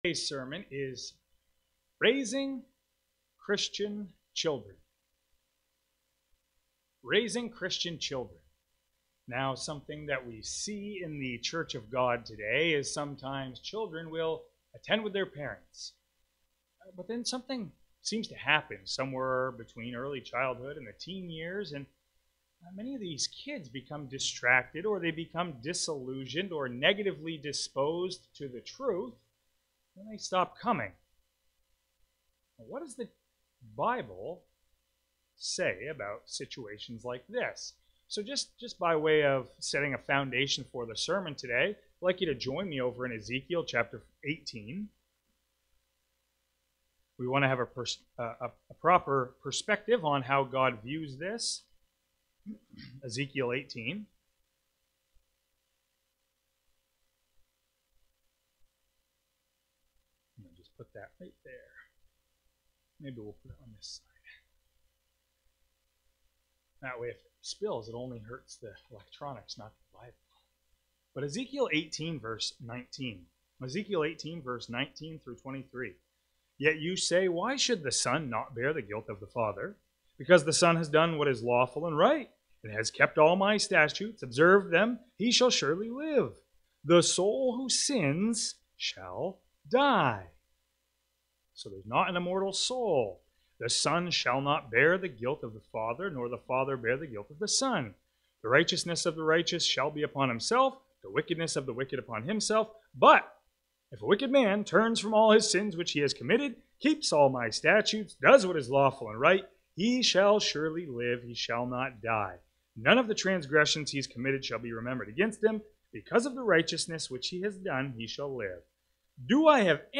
(Microphone batteries went out for a couple seconds at 3:20 but were quickly replaced)